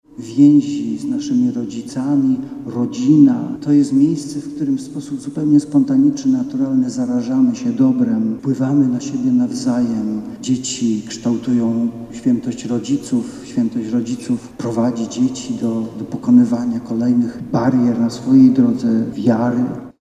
W kościele akademickim świętej Anny w Warszawie w niedzielę odprawiono mszę świętą odpustową.